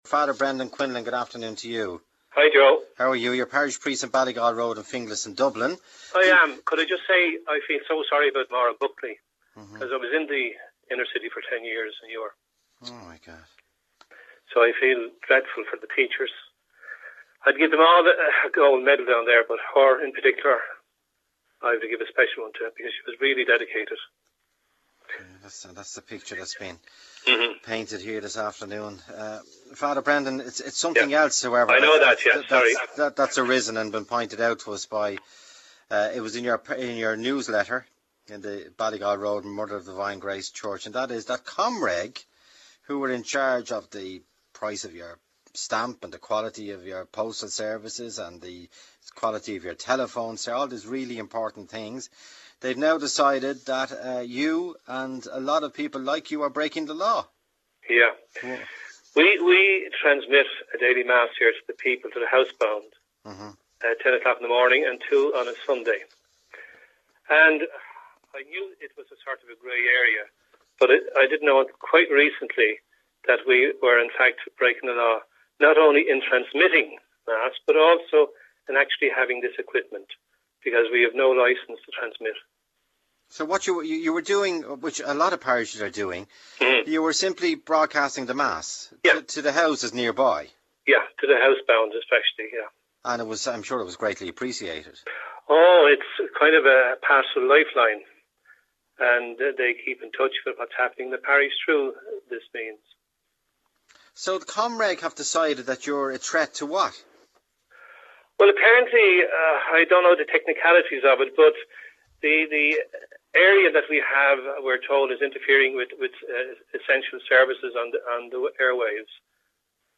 This story was the subject of an interesting segment (22:25, 10.3 MB) on RTE Radio 1's Liveline afternoon chat program. Several pirate priests called in, as well as parishioners who lamented the loss of the service. At least one priest who called in admitted that he knew the practice was illegal, but was adamant in his belief that the benefits of the service his church-station provided outweighed the licensing regulations which prevent such stations from existing.